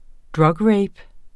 Udtale [ ˈdɹʌgˌɹεjb ]